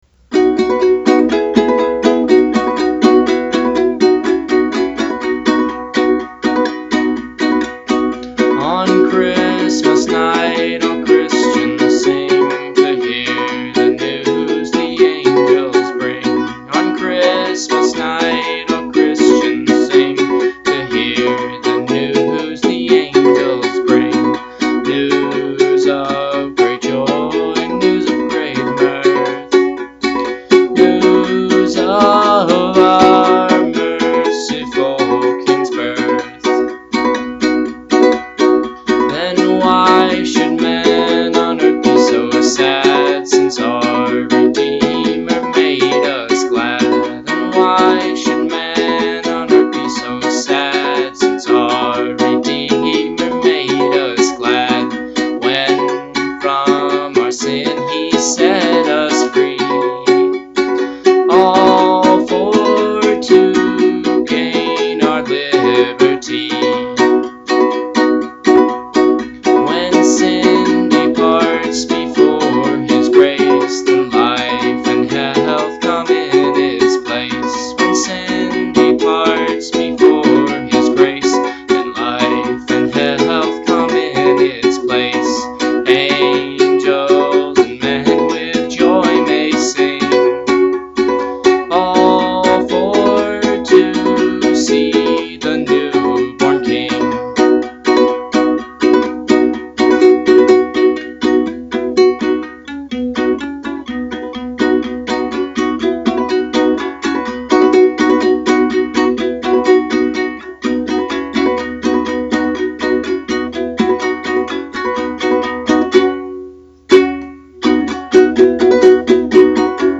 ukulele and voice
Words: English traditional
I played this on the ukulele in the key of B.